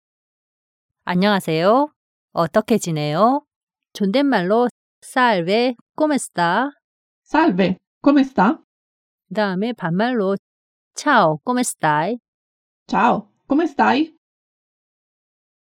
Salve, come sta? ㅣ싸알베 꼬메 스따ㅣ
Ciao, come stai? ㅣ챠오 꼬메 스따이ㅣ